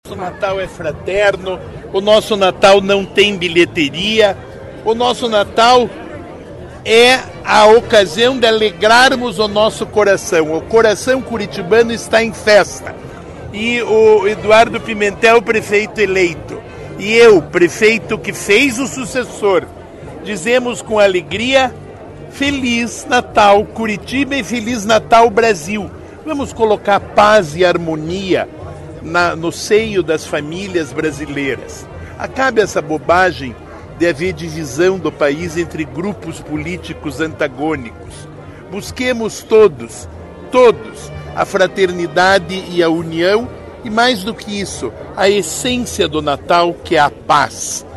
O prefeito Rafael Greca, reafirmou, em entrevista coletiva, que todas as atrações de Natal em Curitiba são gratuitas.